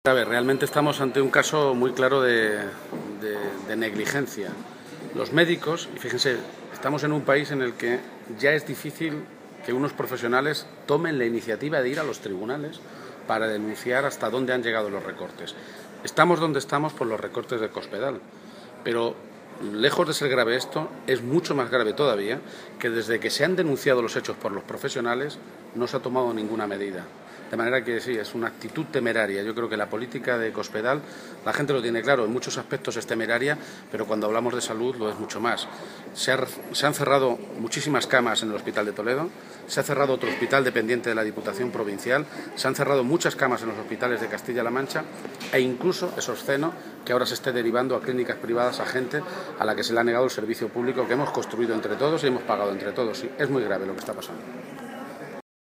García-Page se pronunciaba de esta manera esta mañana, en el Senado, a preguntas de los medios de comunicación, en relación a una información revelada esta mañana por la Cadena Ser en la que se dice que los médicos del servicio de urgencias han denunciado ante el Juzgado de Guardia y ante el Colegio de Médicos el colapso de las urgencias, la imposibilidad física de tratar a los pacientes e, incluso, el fallecimiento de dos personas en los pasilllos de las propias urgencias.
Cortes de audio de la rueda de prensa